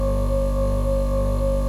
chatterbox-motor.wav